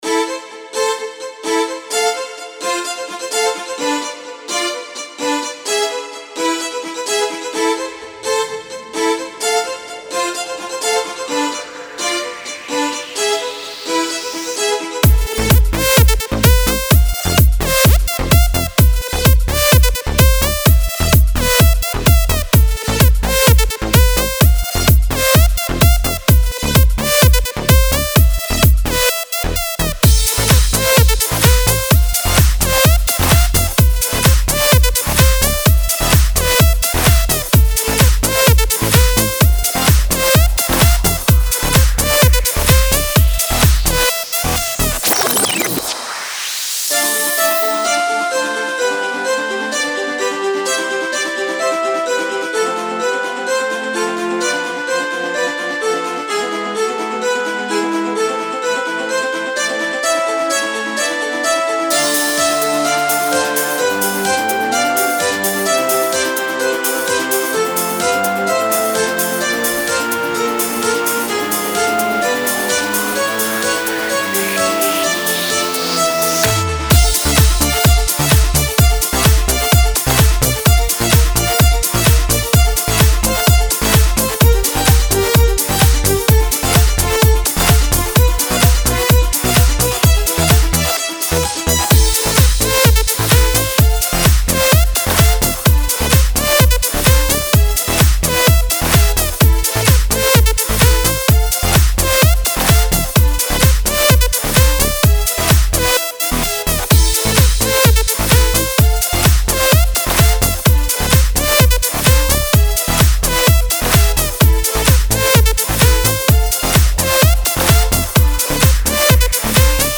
Жанр- Электронная музыка, Популярная музыка